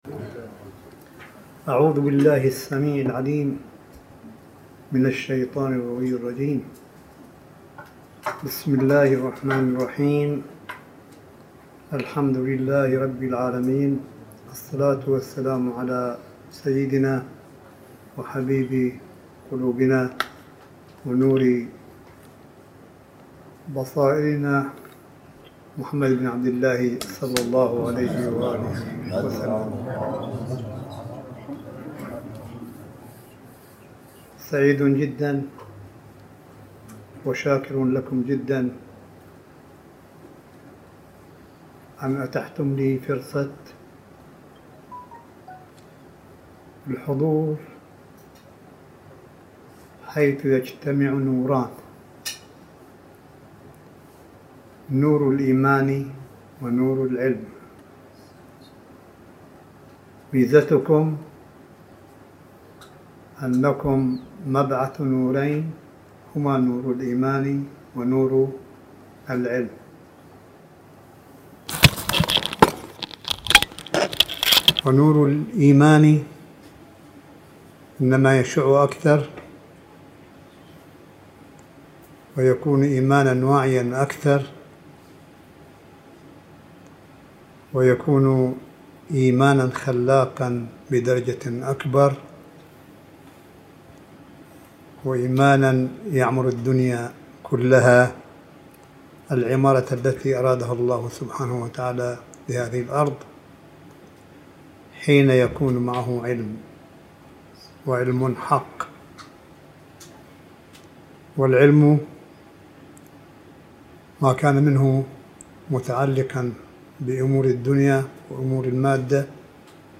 ملف صوتي لكلمة آية الله قاسم في محفل شهداء الثورة الإسلامية ومدافعي الحرم – قم المقدسة 12 يونيو 2019م